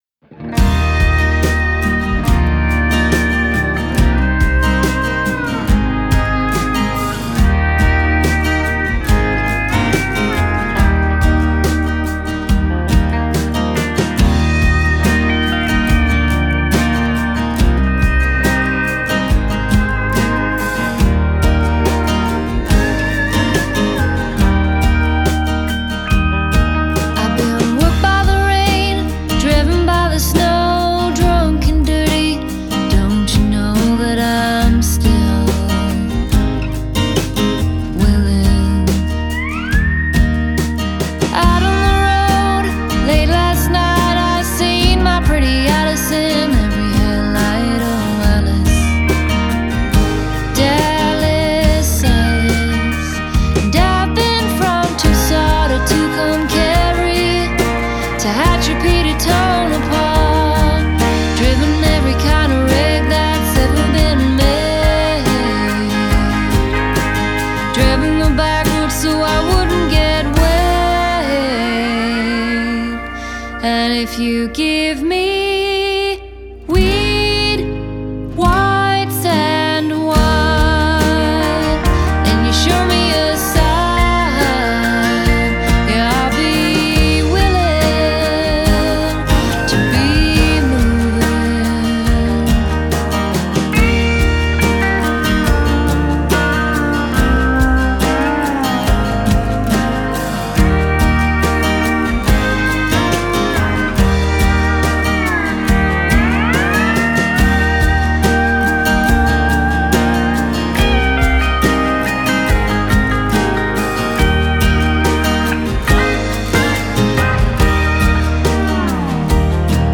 Genre: Folk, Singer-Songwriter